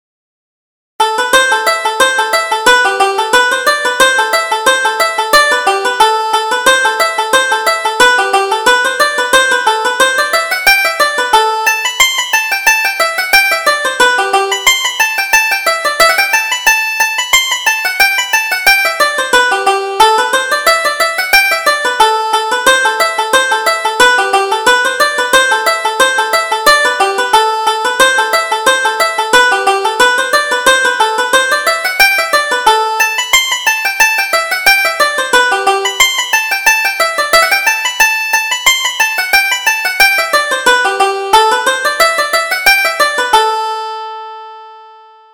Reel: The Smoky House